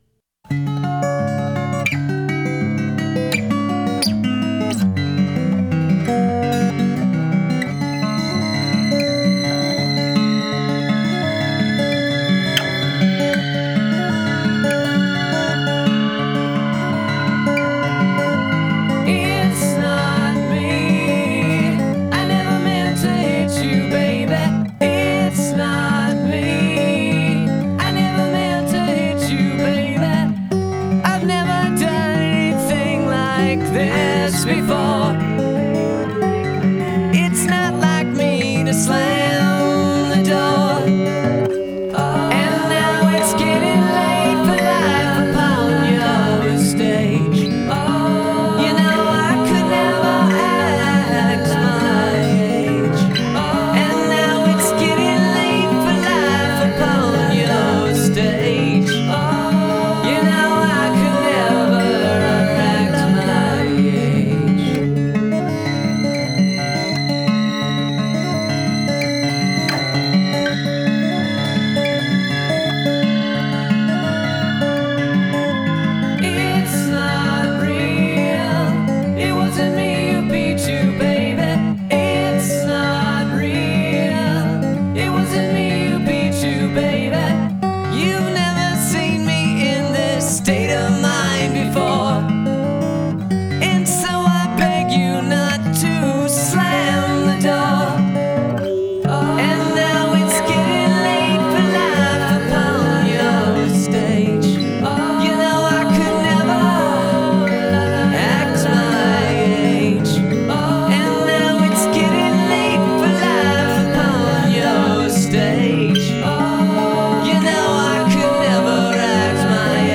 self-produced and home-recorded